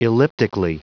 Prononciation du mot elliptically en anglais (fichier audio)
Prononciation du mot : elliptically